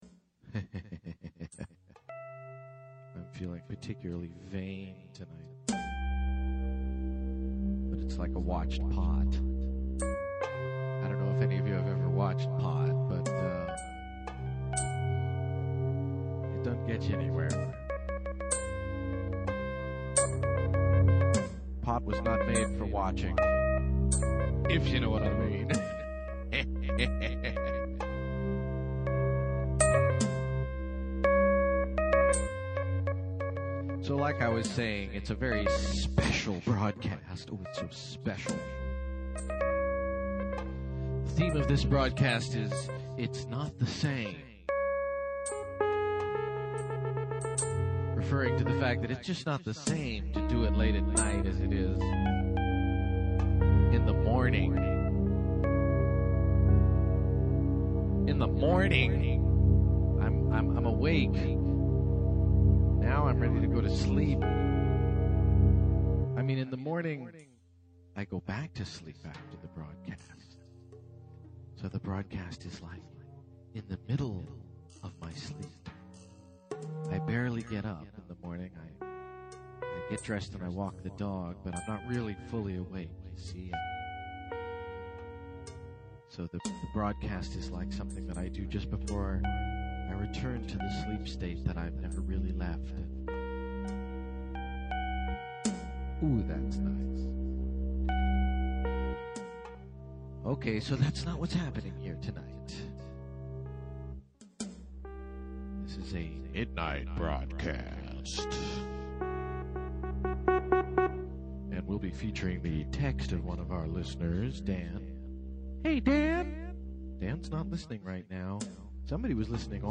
We receive a random caller which is always a treat!